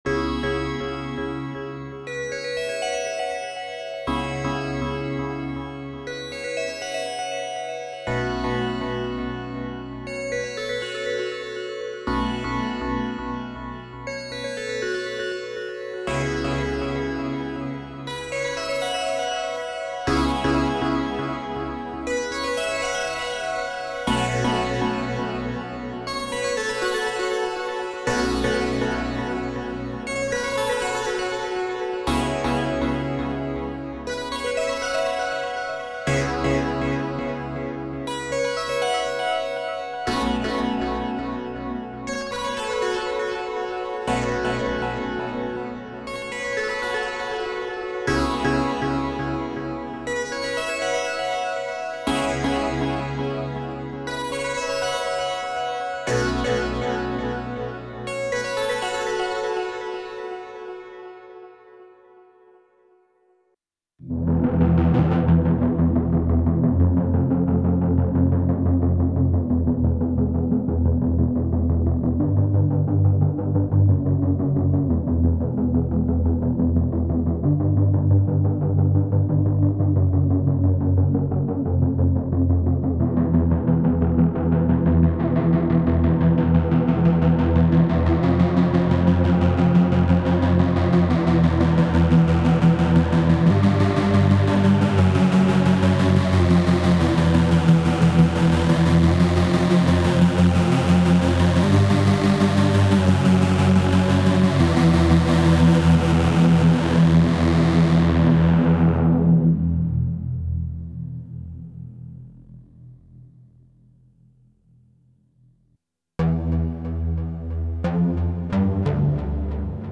some short ARTEMIS demos from me, the first sound demonstrates the impact of the waveshaper dial. The rest is “played” on the computer keyboard, just to provide some more impressions. Mostly custom sounds, only Artemis, no ext treatment - enjoy;)